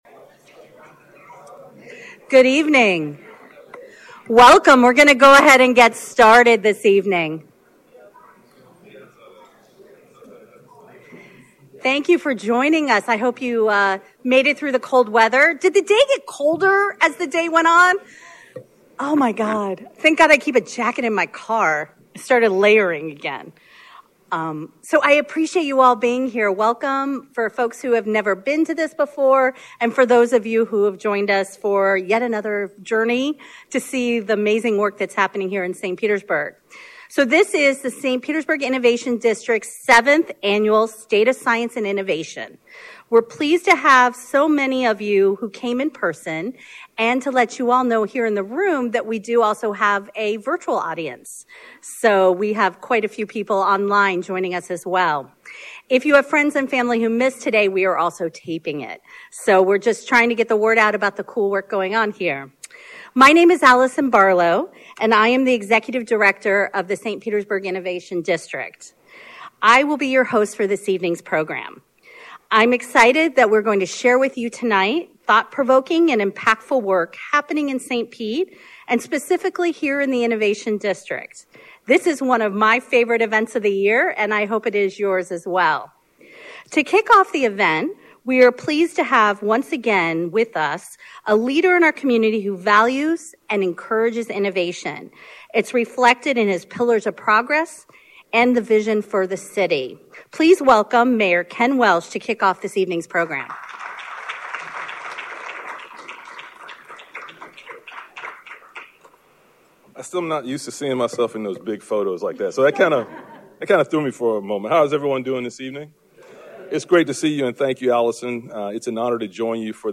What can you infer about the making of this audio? The 7th annual State of Science and Innovation took place on January 15, 2025 at Johns Hopkins All Children's Hospital. The in person and online audiences heard from five innovators and one special guest about the remarkable work they are doing.